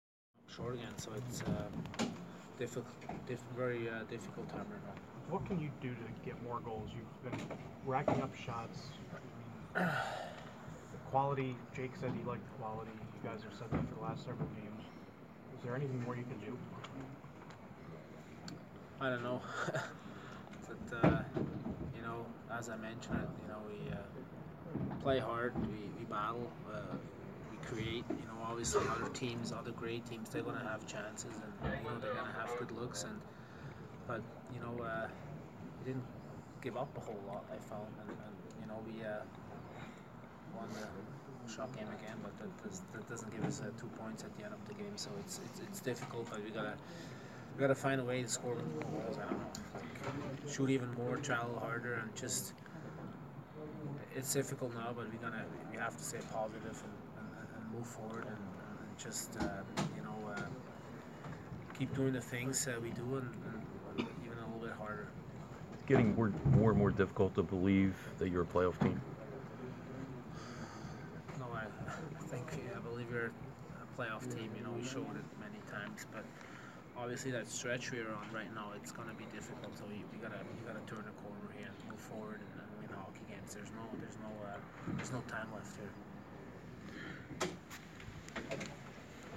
A frustrated Mark Streit Sums Up the Stadium Series Loss.